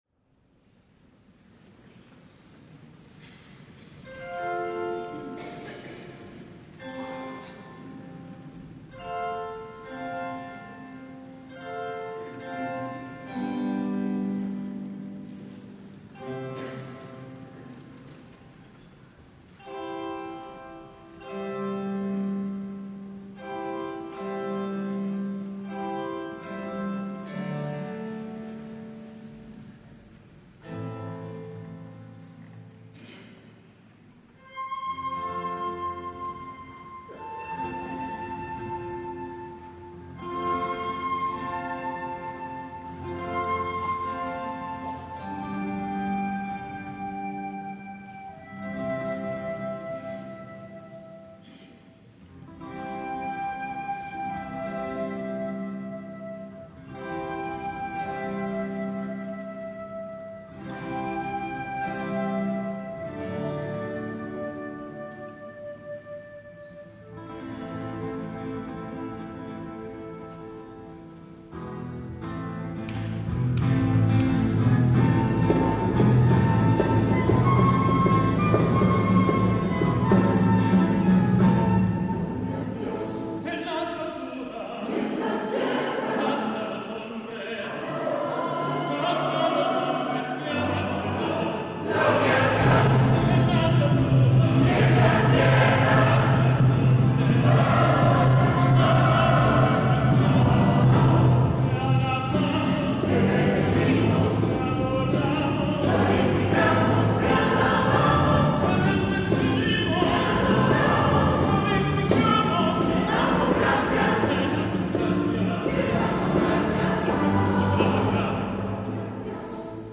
S. Gaudenzio church choir Gambolo' (PV) Italy
audio del concerto